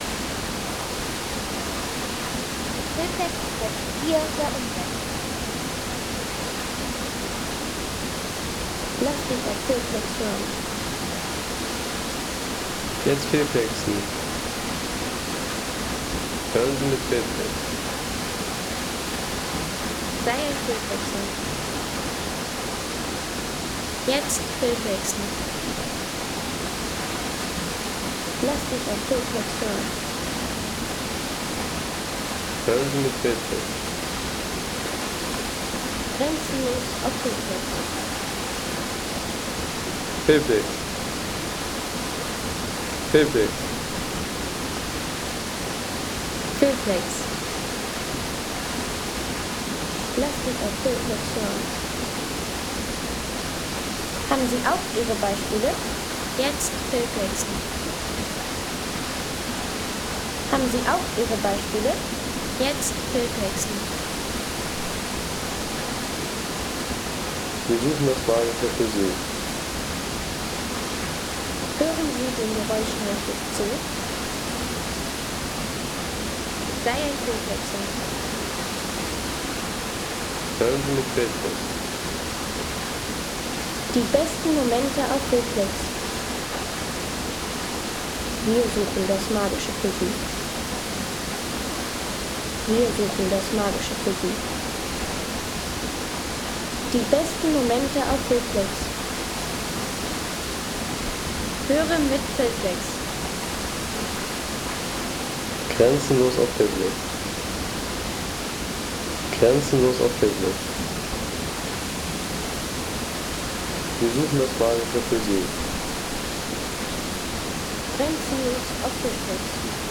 Veliki Slap Home Sounds Landschaft Wasserfälle Veliki Slap Seien Sie der Erste, der dieses Produkt bewertet Artikelnummer: 270 Kategorien: Landschaft - Wasserfälle Veliki Slap Lade Sound.... Veliki Slap – Das tosende Tor zu den Plitvicer Seen.